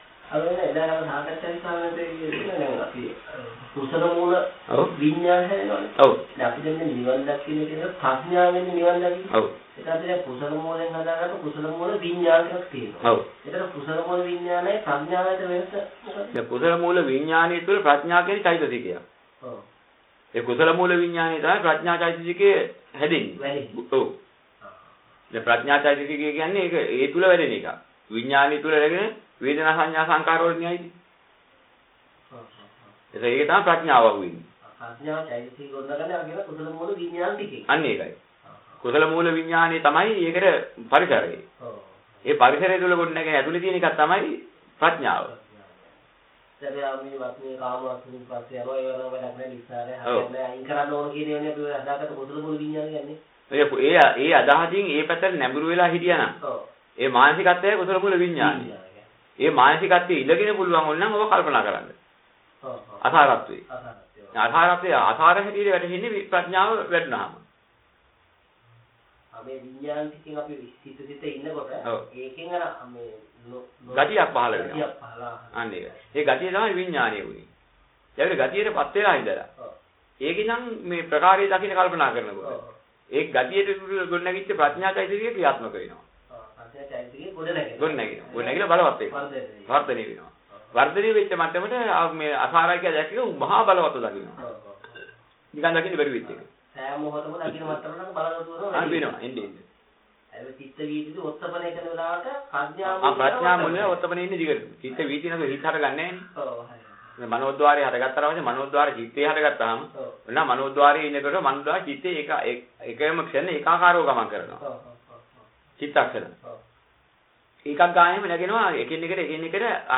මෙම දේශනාවේ අඩංගු ගාථා හෝ සූත්‍ර කොටස්